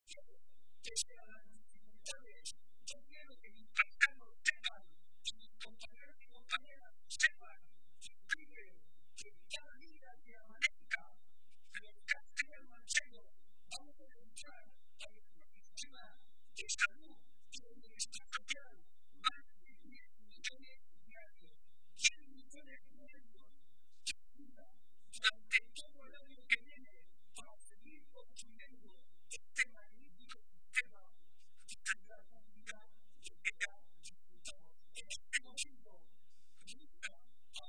Barreda realizó estas manifestaciones durante la celebración de la tradicional comida navideña del PSOE de Toledo, que ha tenido lugar hoy en la capital regional, y a la que también asistió la ministra de Sanidad y Política Social, Trinidad Jiménez.